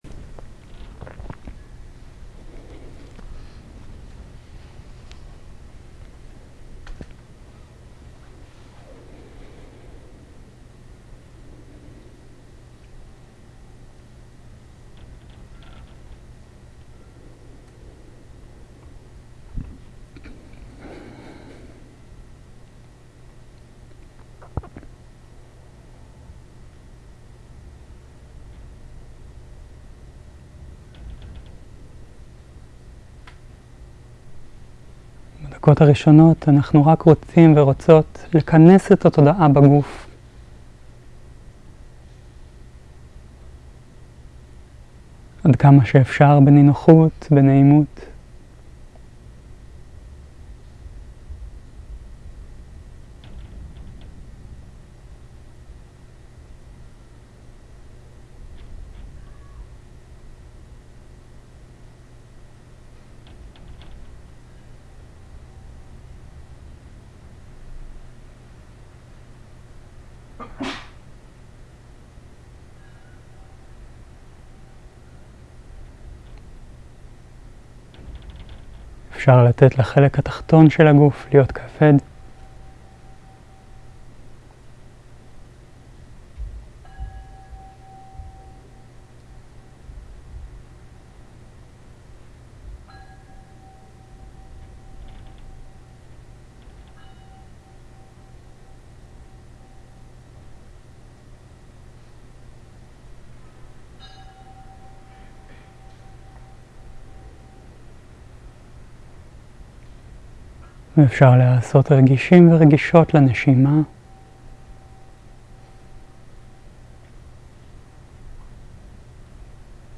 מדיטציה מונחית - אנאטה